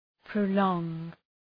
{prə’lɔ:ŋ}
prolong.mp3